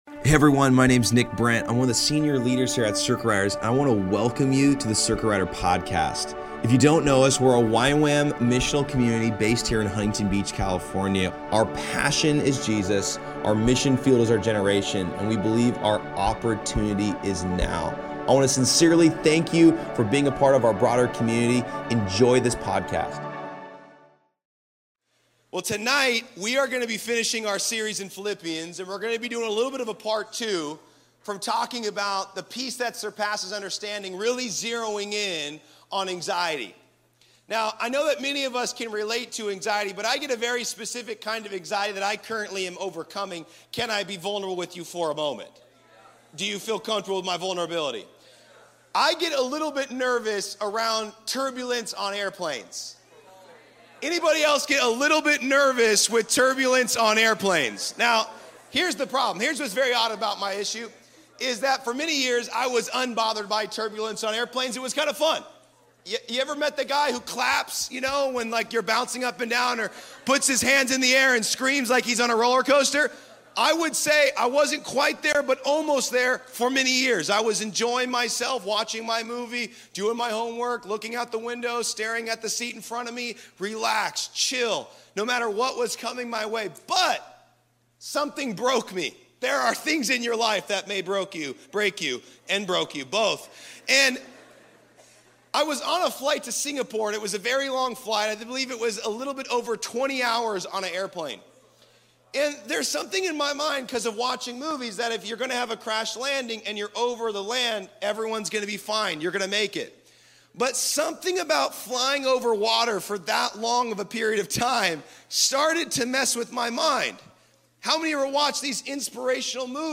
At Circuit Riders Monday Nights on May 27th 2024.